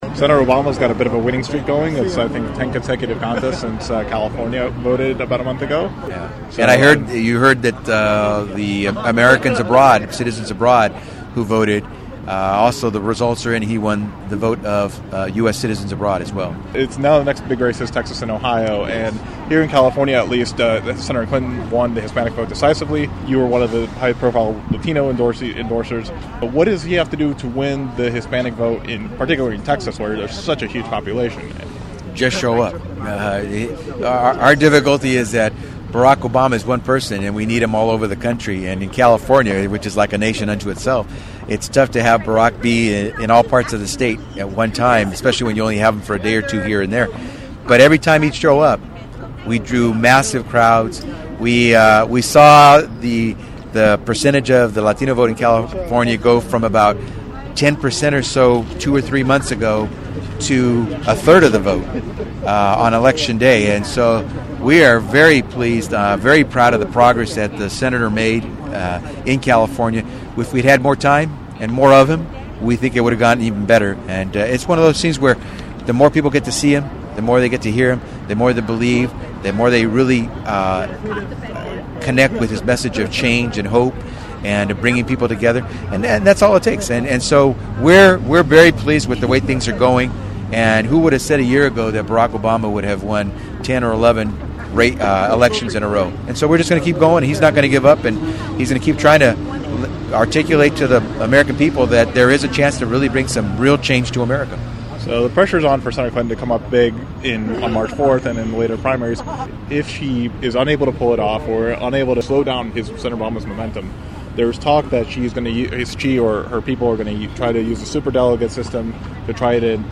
Becerra Politics Interview
becerrainterview.mp3